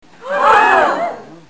SFX惊呼111音效下载
SFX音效